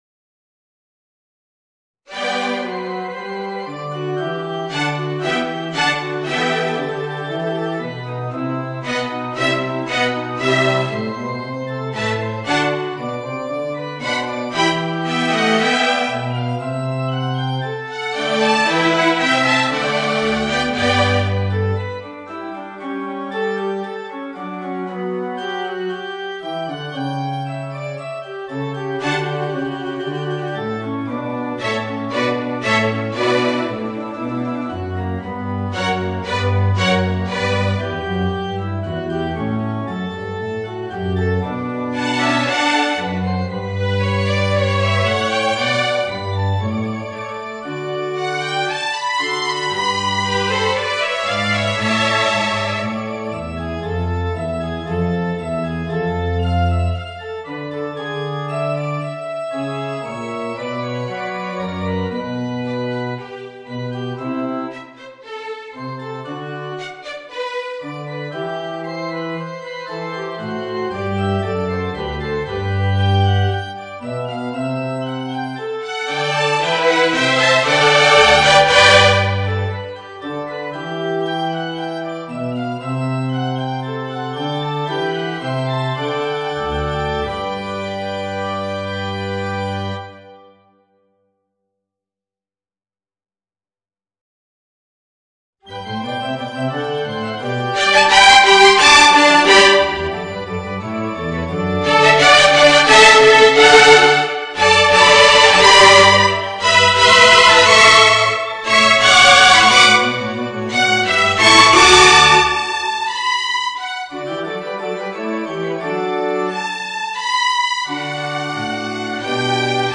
Voicing: 2 Violins, Viola and Organ